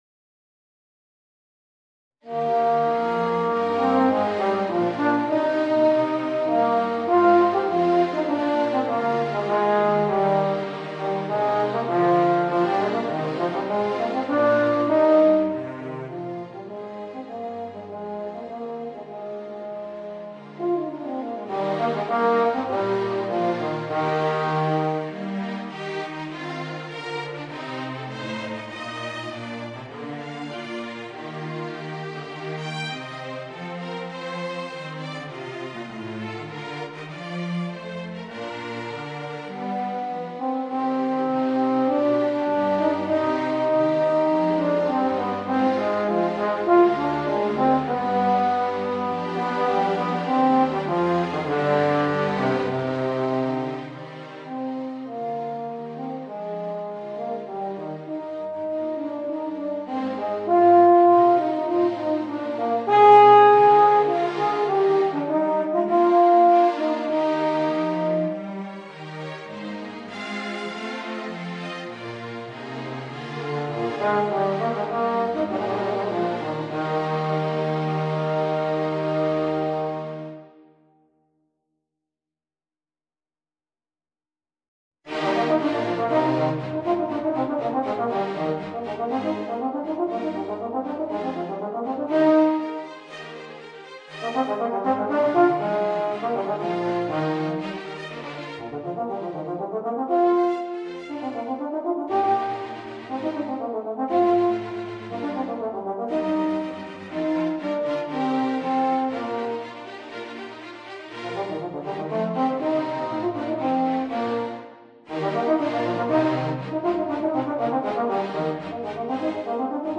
Voicing: Trombone and String Orchestra